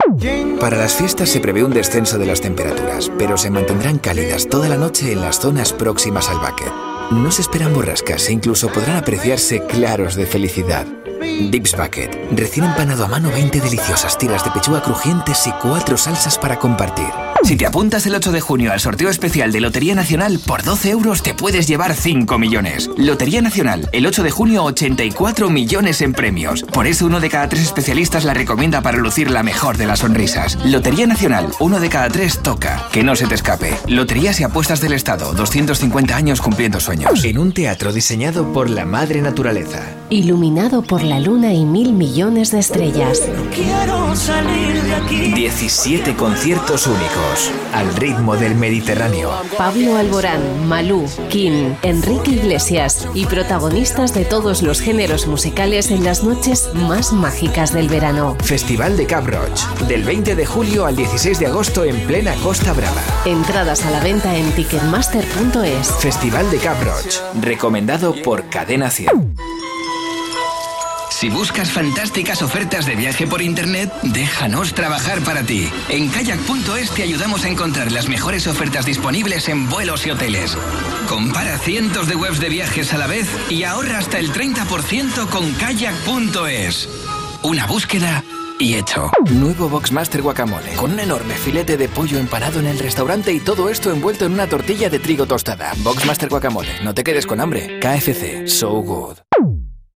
Sprecher spanisch für Hörfunk und Werbung
Sprechprobe: Werbung (Muttersprache):